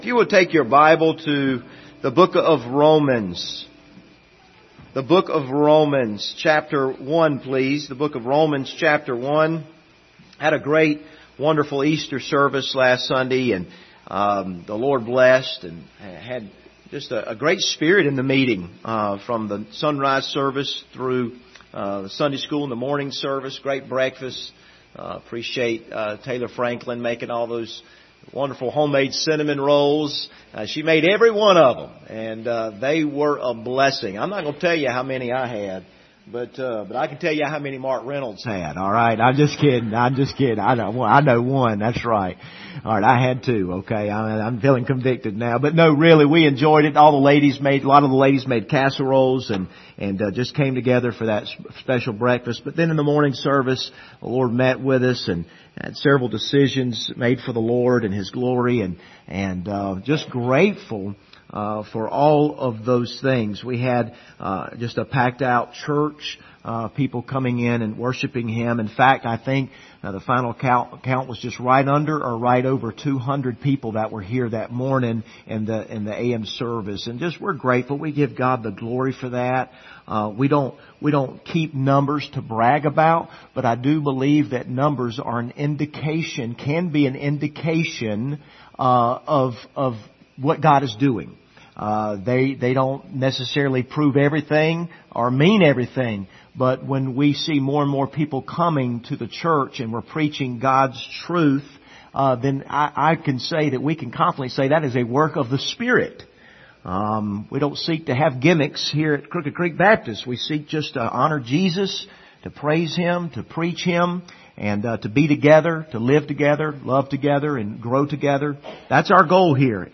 The Just Shall Live By Faith Passage: Romans 1:18-21 Service Type: Sunday Morning « What Will You Do With Jesus?